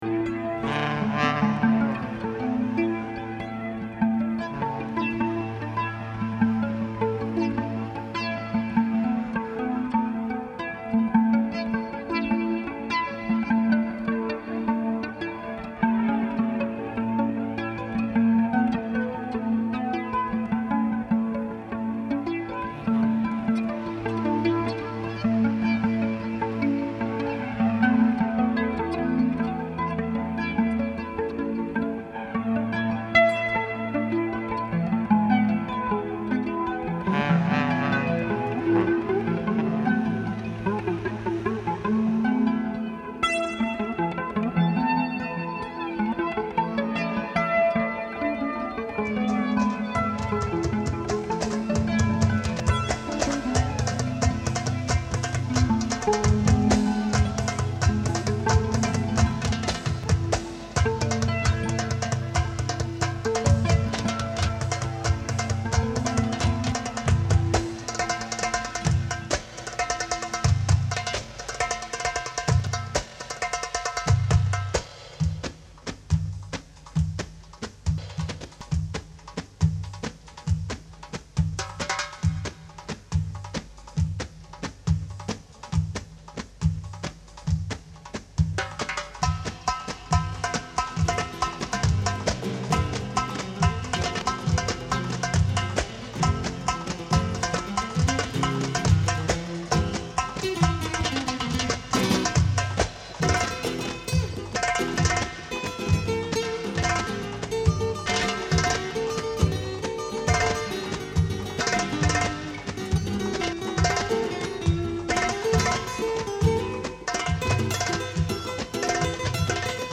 this mix comes from the day after da's birthday party.